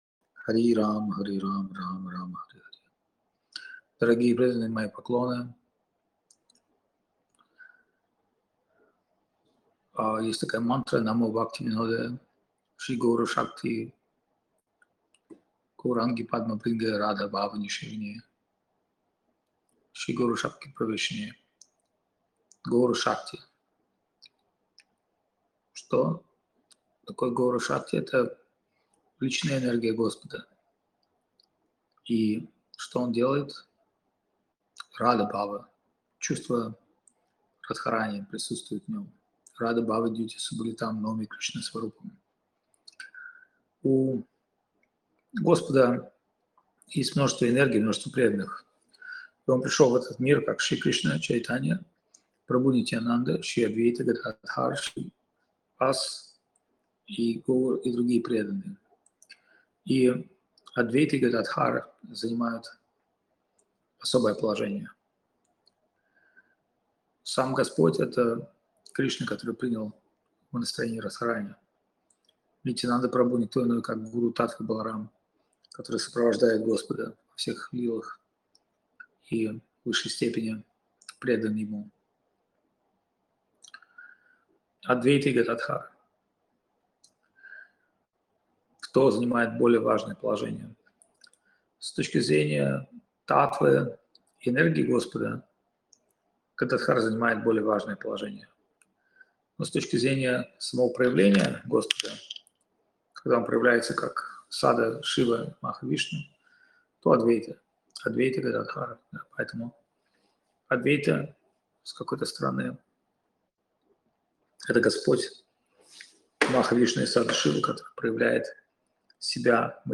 Лекции полностью